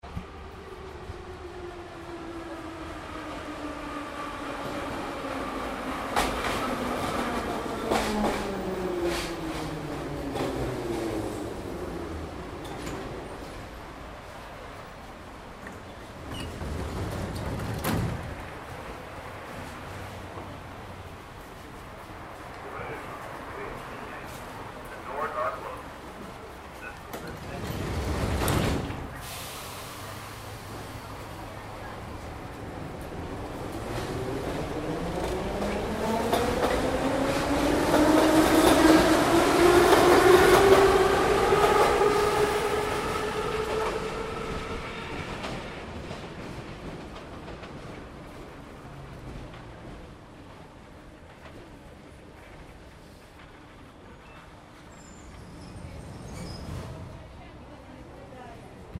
Станция метро: приход и уход поезда